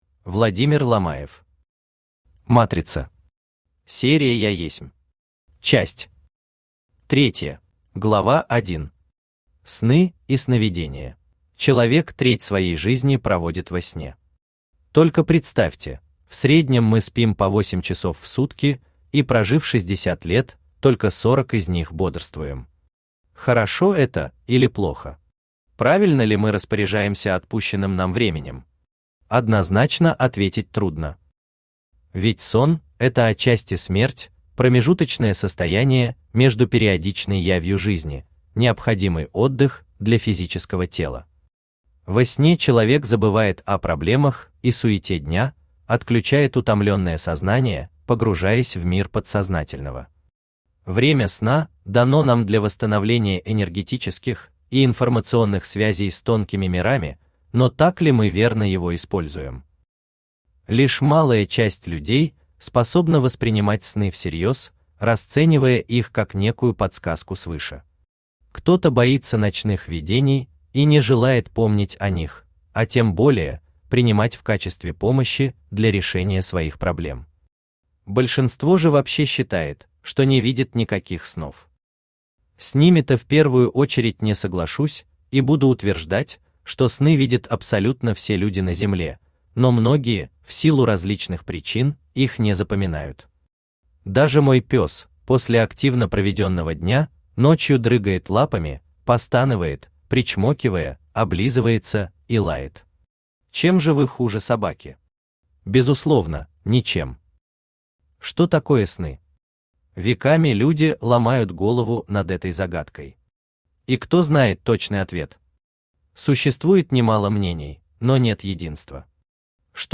Аудиокнига Матрица. Серия «Я есмь». Часть III | Библиотека аудиокниг
Часть III Автор Владимир Федорович Ломаев Читает аудиокнигу Авточтец ЛитРес.